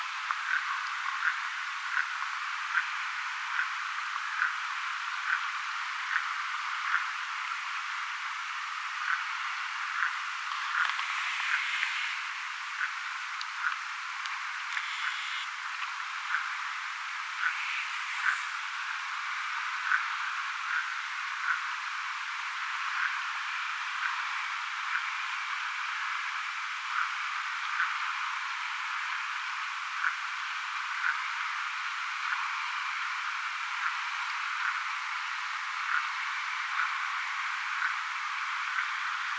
isokoskelon huomioääntelyä
Ilmeisesti nuori isokoskelo ääntelee ihan rannan tuntumassa.
ilmeista_isokoskelon_huomioaantelya.mp3